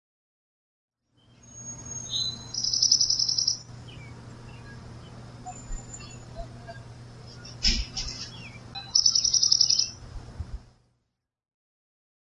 环境声音
描述：户外自然，h 鸟类的歌声和风的声音。
Tag: 环境 现场录音 自然